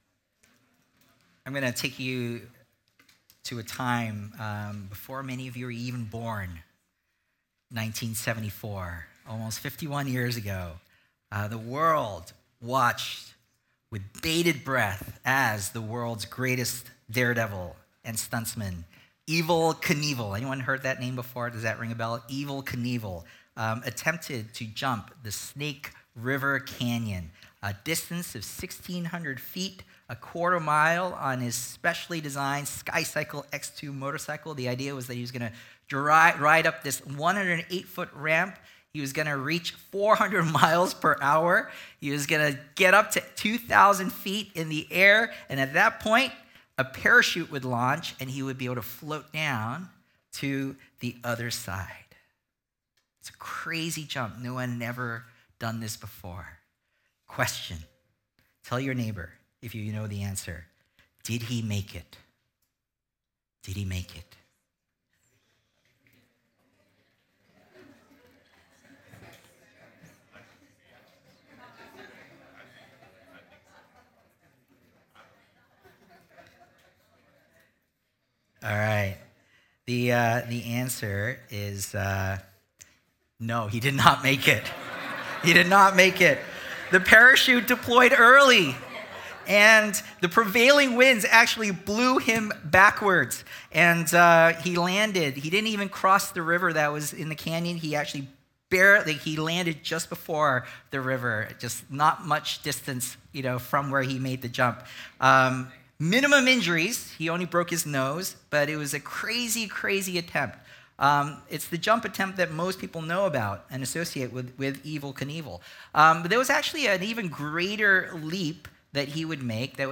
Sermons | Symphony Church